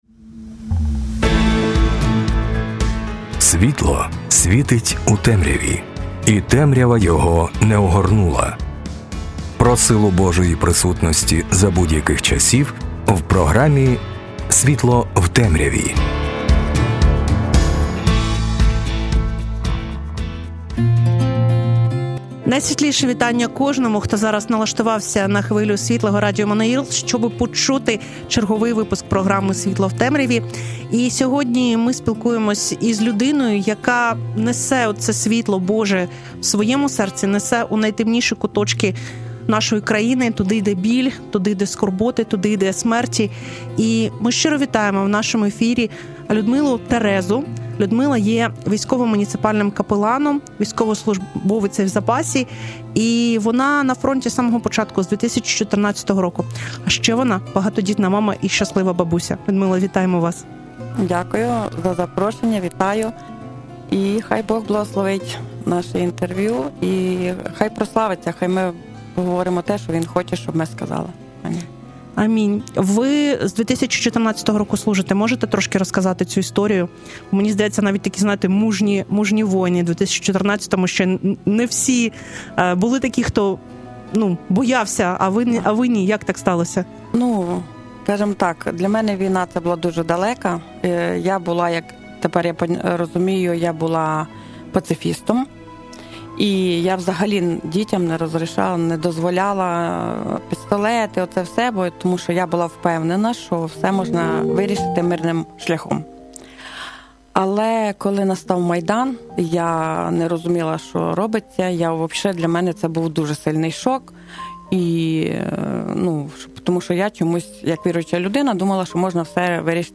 Ця розмова про Божі чудеса на війні та розуміння людей, які повертаються з поля бою.